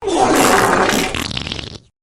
Free SFX sound effect: Samurai Farts.
Samurai Farts
Samurai Farts.mp3